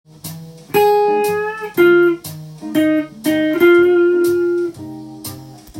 譜面通り弾いてみました
Fマイナーペンタトニックスケールを使いブルージーな感じになっています。